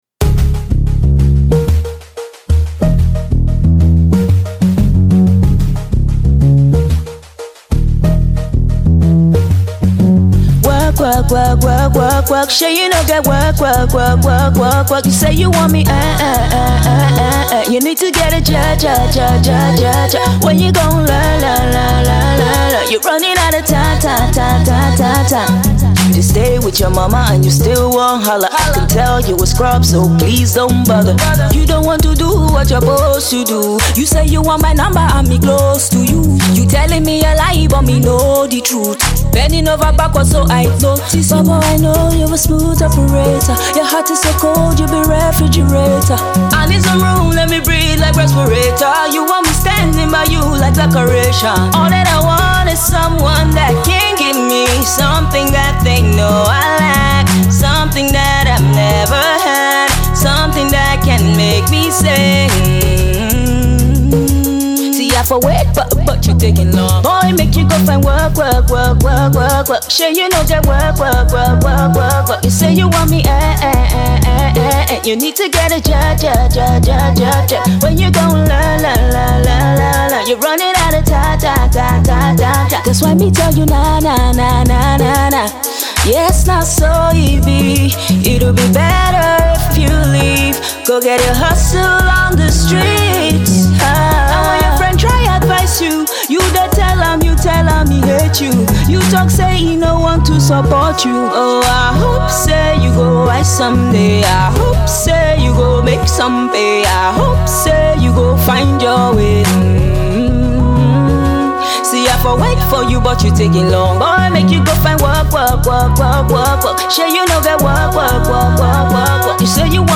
a cover